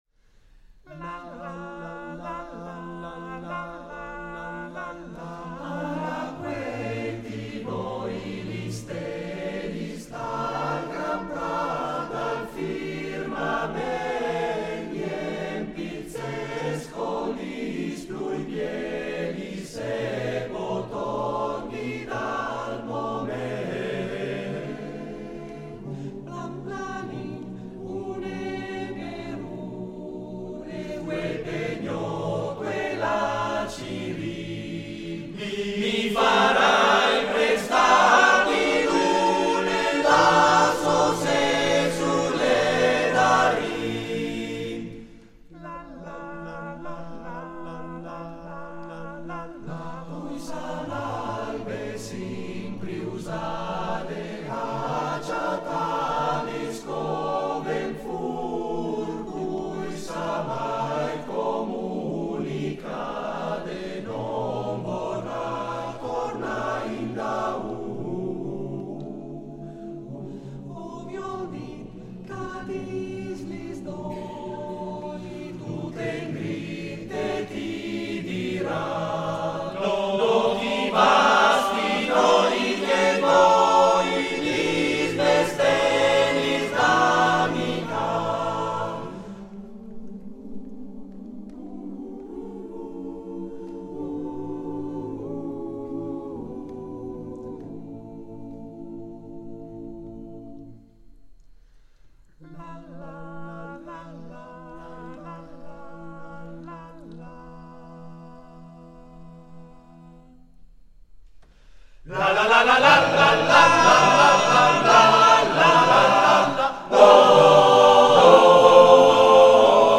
: Registrazione live